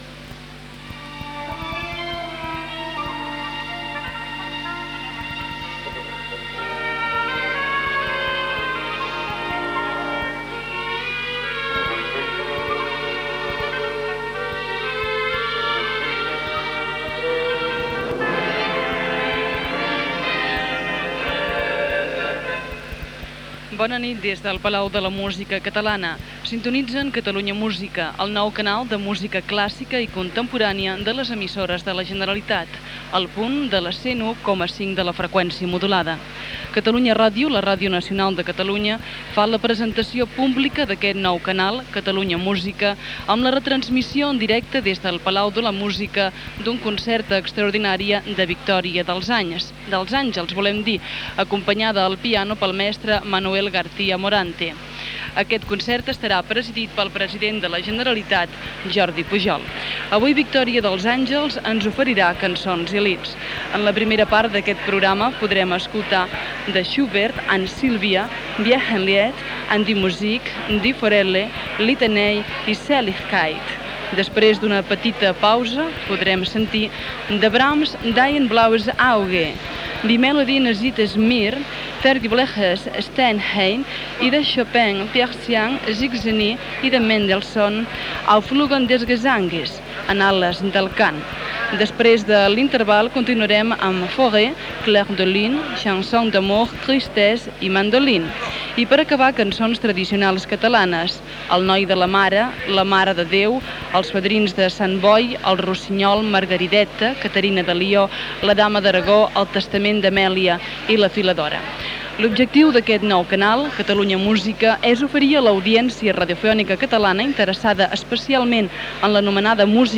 Transmissió del primer concert ofert per l'emissora des del Palau de la Música Catalana i protagonitzat per Victòria dels Àngels.
Musical